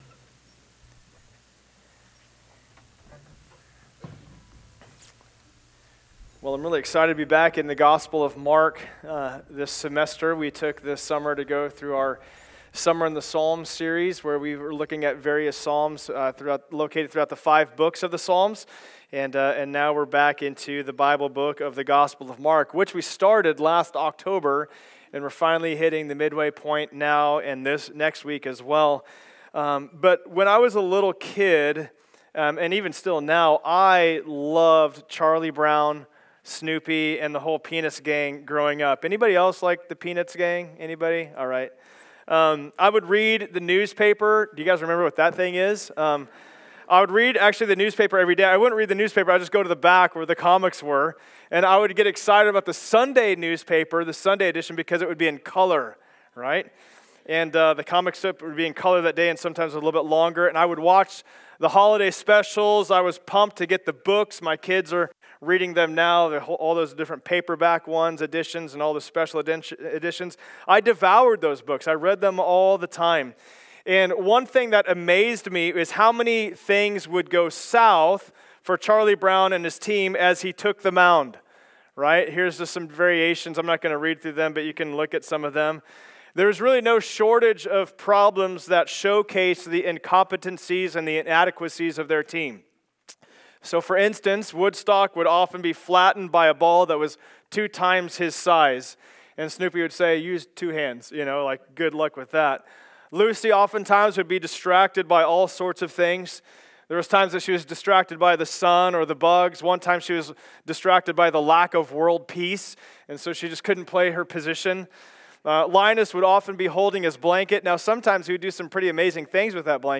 Preacher
Passage: Mark 8:1-21 Service Type: Sunday Service Download Files Notes « Ministry Kick Off 2024 Who Do You Say That He Is?